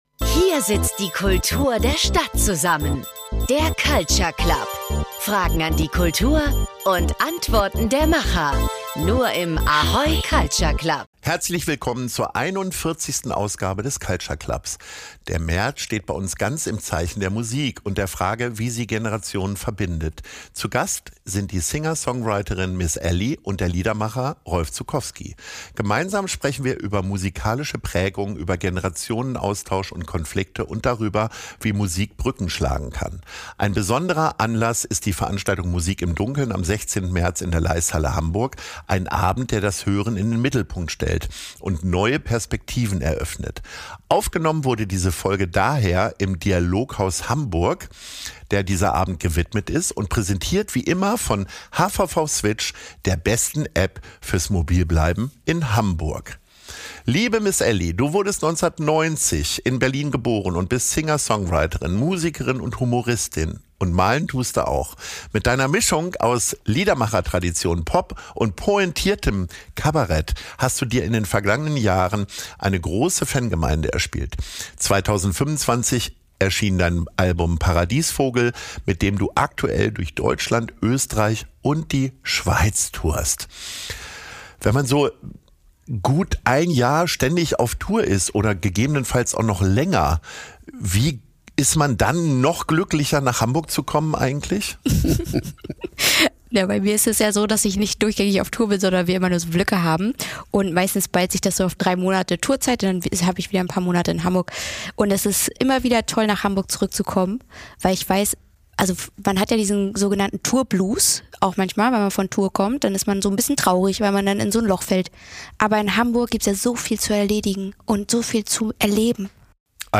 Aufgenommen wurde diese Folge daher im Dialoghaus Hamburg und präsentiert wie immer von HVV Switch, der besten App fürs Mobilbleiben in Hamburg.